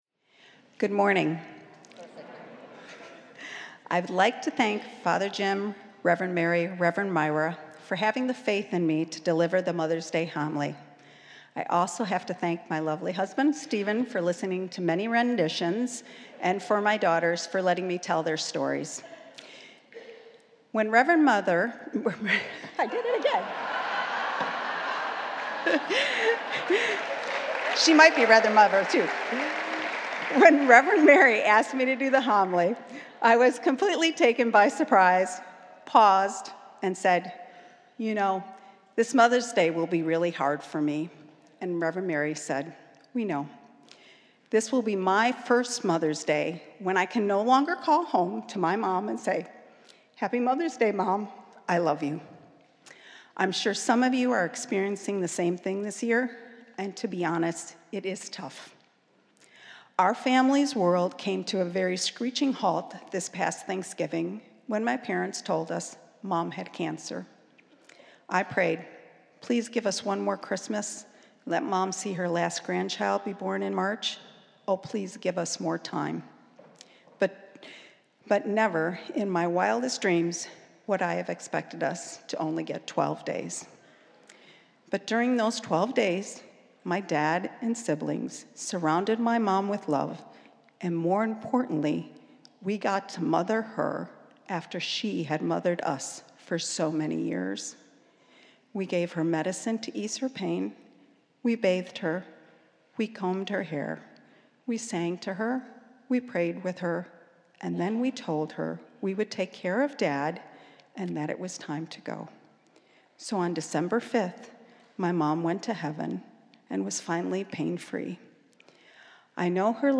Spiritus Christi Mass May 13th, 2018
This is Mother’s Day at Spiritus Christi in Rochester, NY.